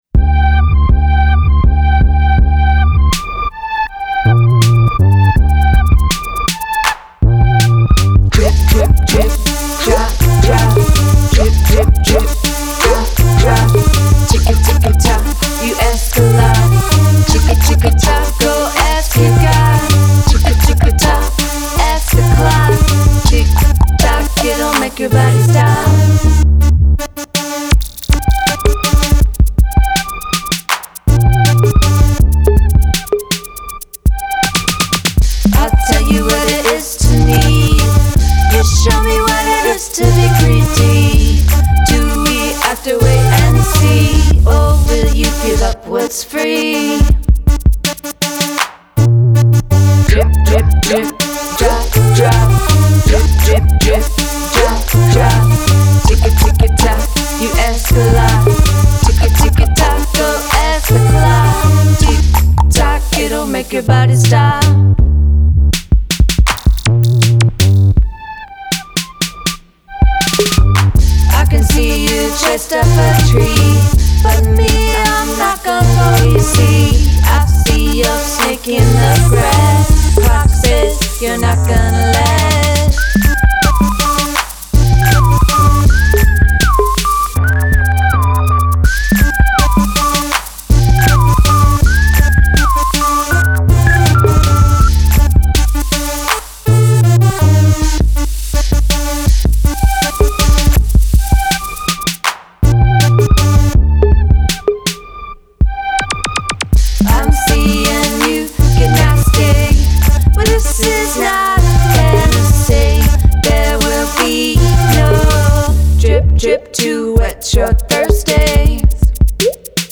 There are hints of R'n'B